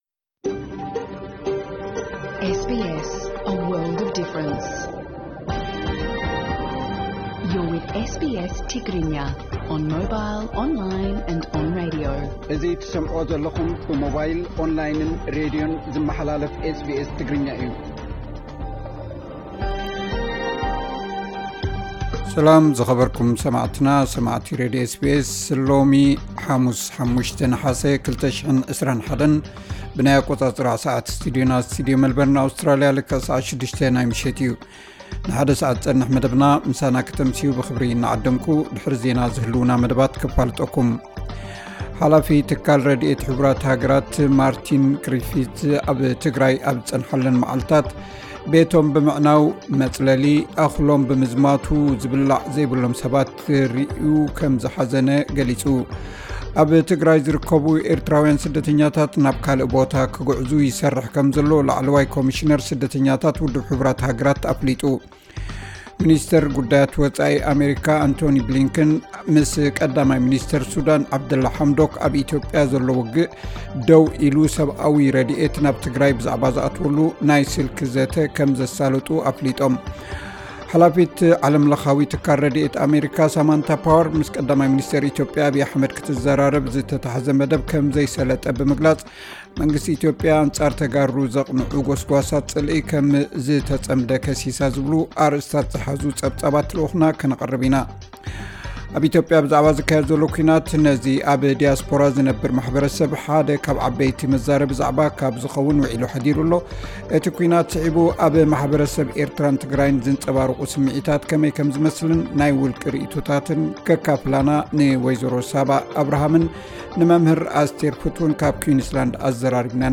ዕለታዊ ዜና 05 ነሓሰ 2021 SBS ትግርኛ